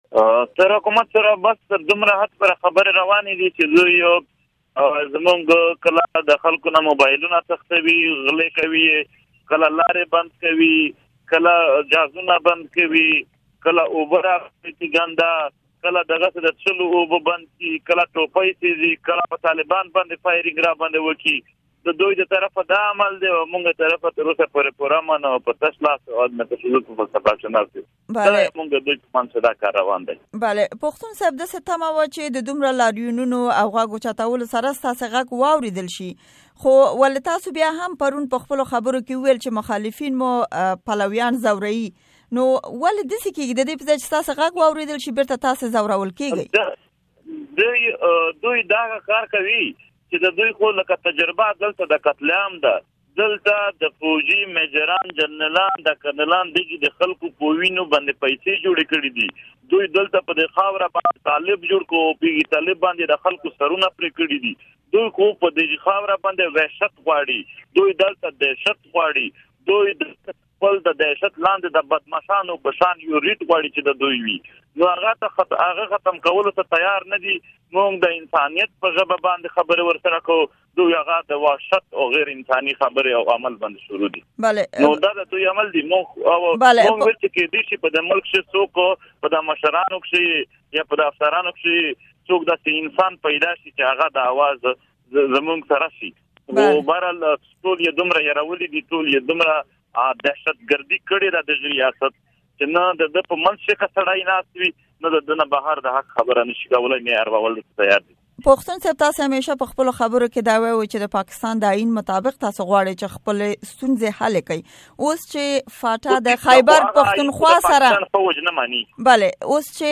امريکا غږ سره د منظور پښتون مرکه